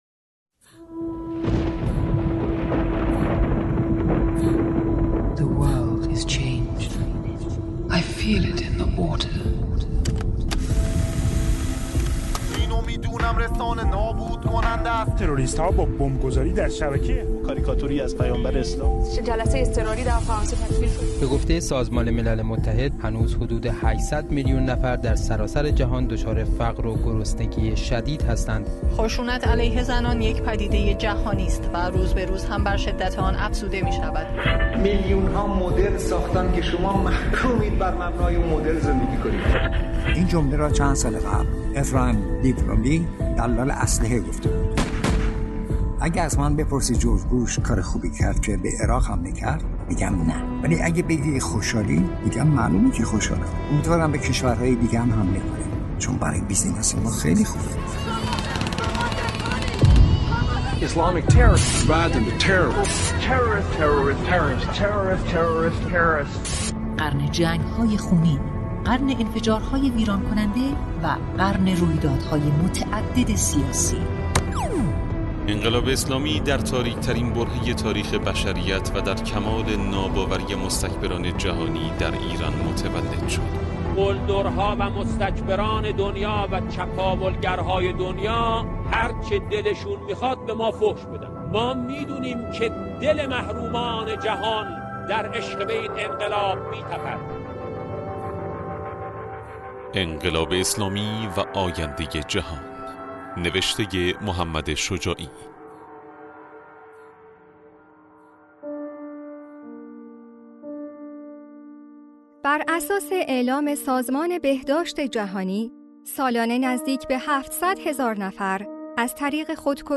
تکیه | مستند صوتی آینده جهان - قسمت اول